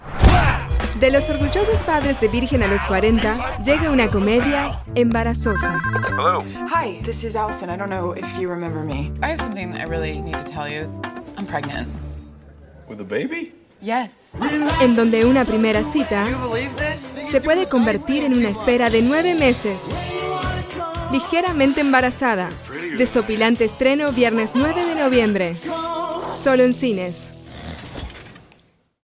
Spanish/English/Portuguese EspaĂ±ol rioplatense, espaĂ±ol uruguayo, espaĂ±ol neutro
Sprechprobe: Sonstiges (Muttersprache):
Español neutro.mp3